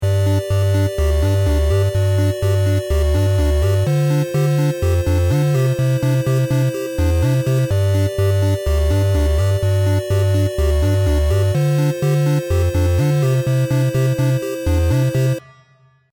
Here are two clips from the early stages of production: